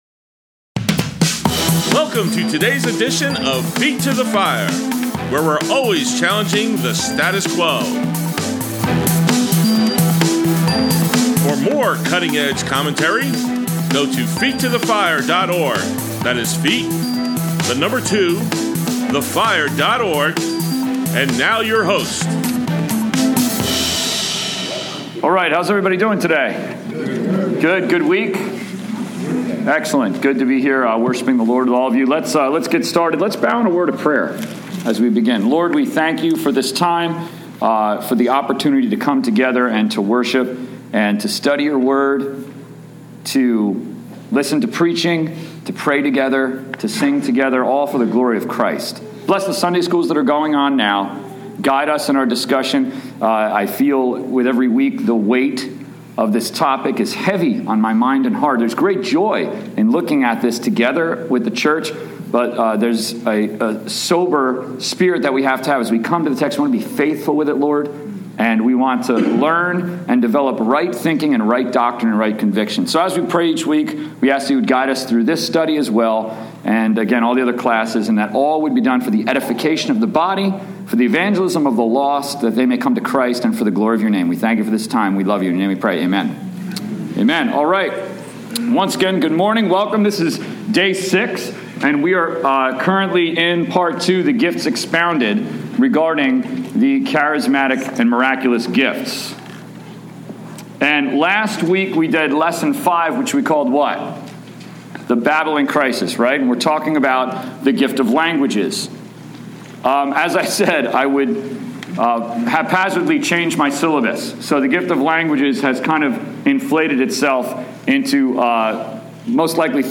Grace Bible Church, Adult Sunday School, 2/8/18